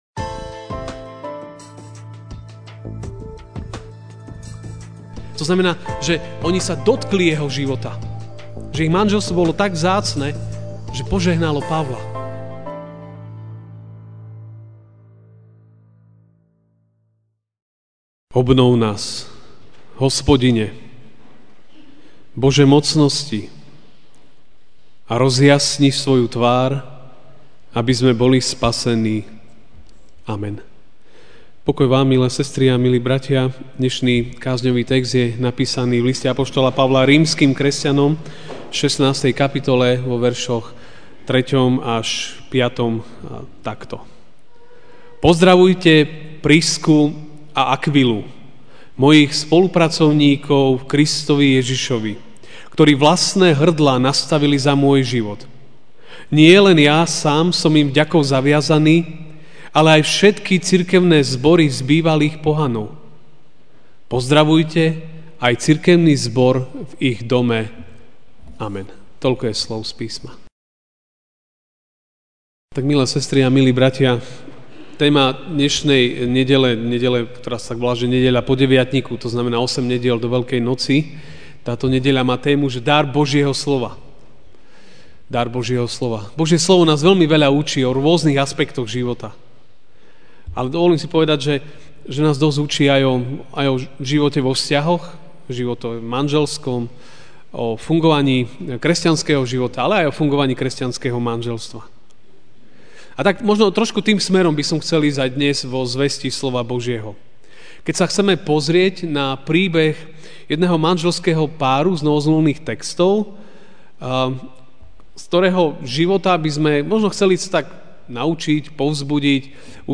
Ranná kázeň: Spolu na ceste: Priscilla a Akvila (Rím. 16, 3-5) Pozdravujte Prisku a Akvilu, mojich spolupracovníkov v Kristovi Ježišovi, ktorí vlastné hrdlá nastavili za môj život.